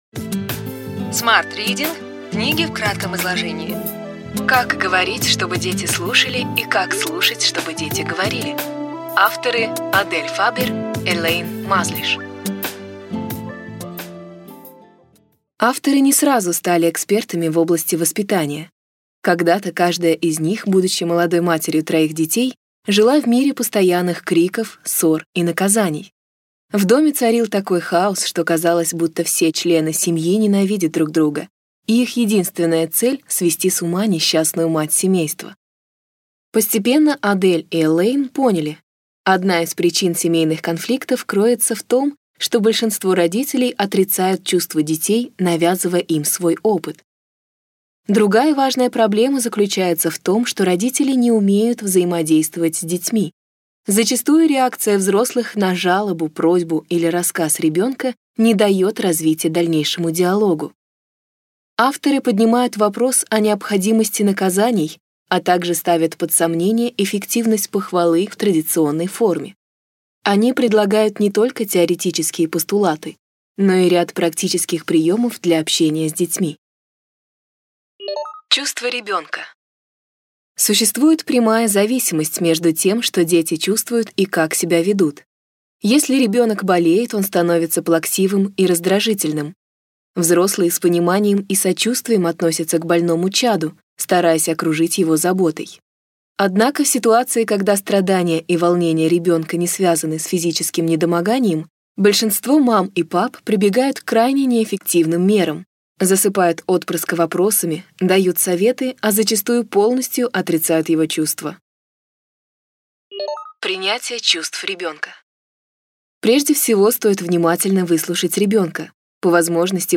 Аудиокнига Ключевые идеи книги: Как говорить, чтобы дети слушали, и как слушать, чтобы дети говорили.